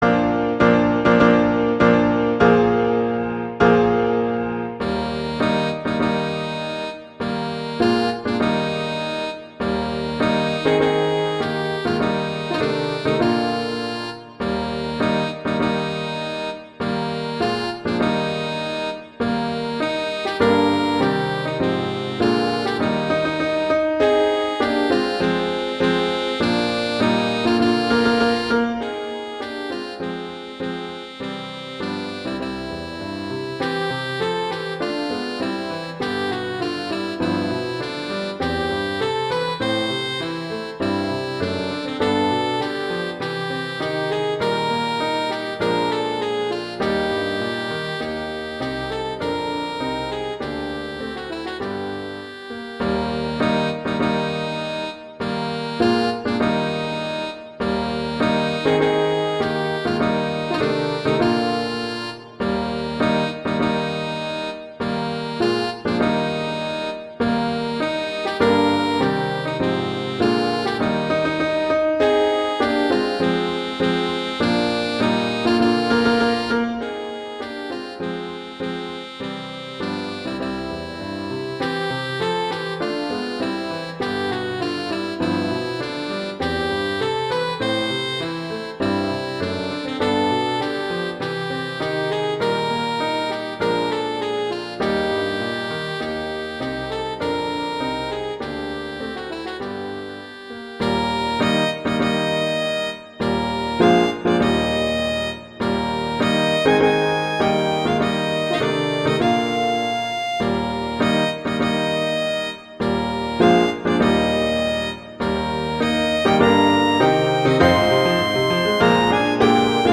Instrumentation: alto saxophone & piano (organ)
arrangements for alto saxophone and piano (organ)
wedding, traditional, classical, festival, love, french